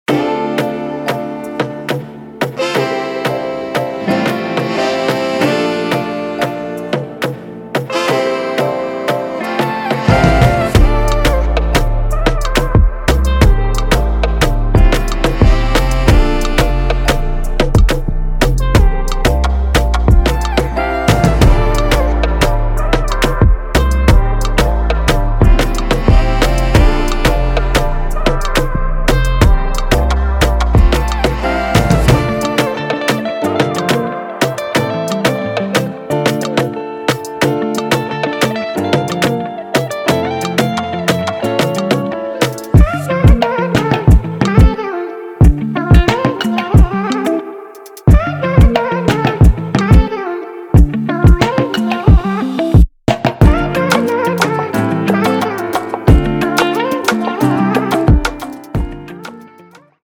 Afropop & Afrobeats